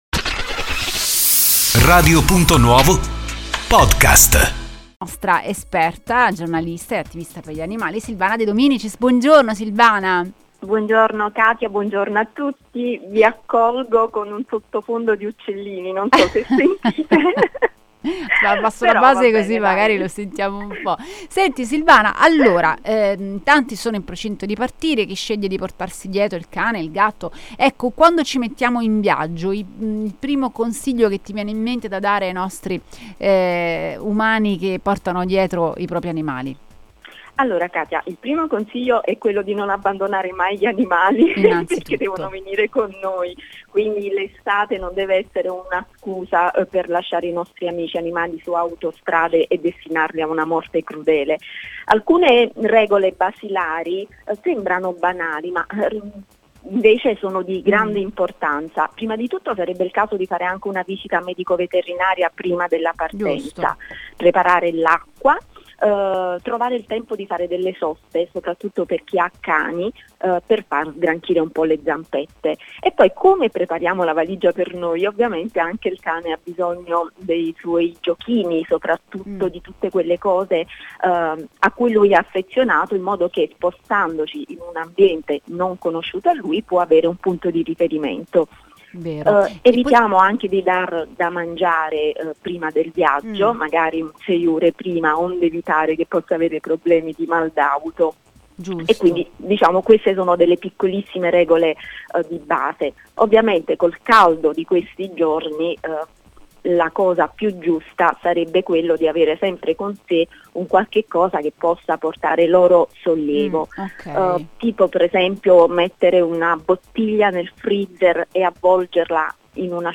Ne abbiamo parlato ad Animali Fantastici con la giornalista